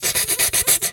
pgs/Assets/Audio/Animal_Impersonations/snake_2_hiss_03.wav at master
snake_2_hiss_03.wav